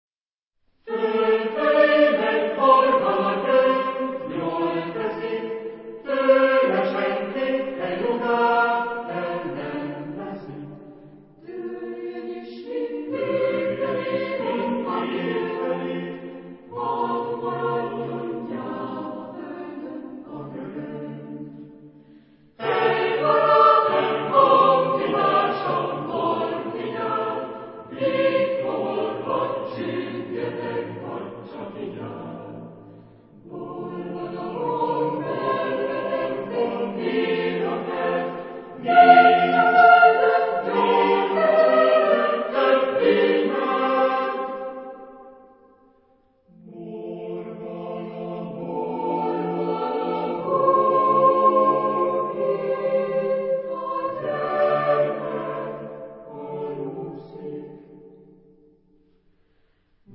Género/Estilo/Forma: contemporáneo ; Coro
Tipo de formación coral: SATB  (4 voces Coro mixto )